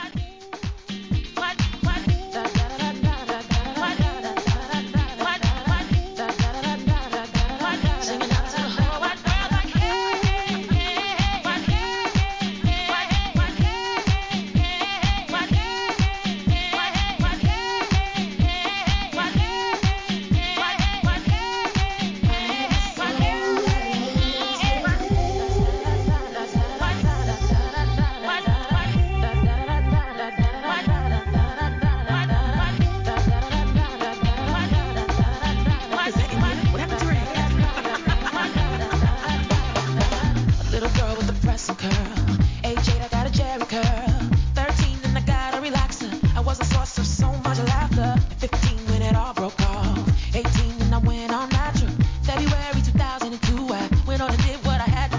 HOUSE REMIX!!